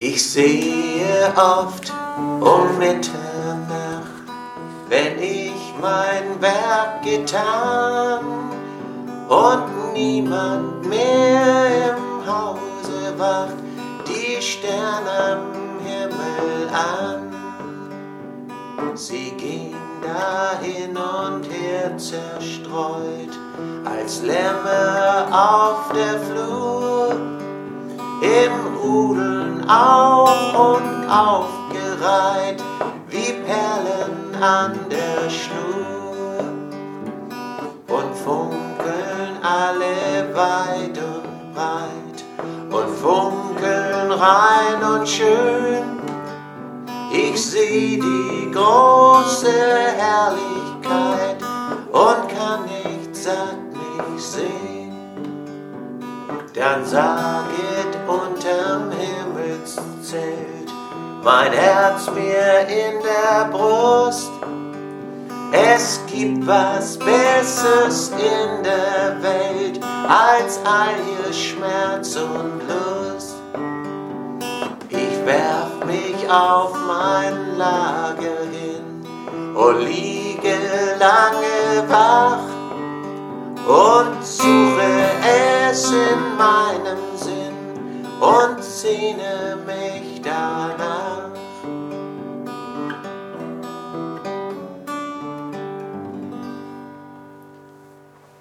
Kinderlied